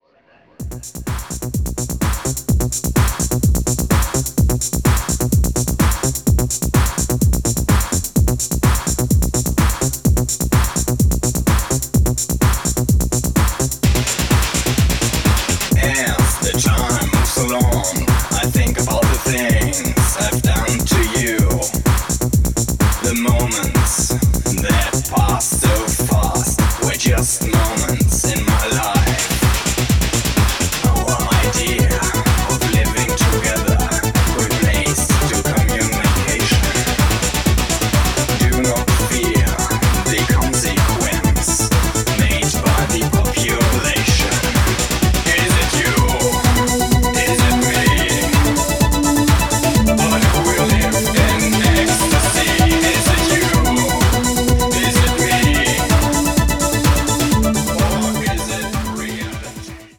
ほぼトランス寸前のパワフルなアルペジオが主導するポストEBM系テクノが今また新鮮に響きます。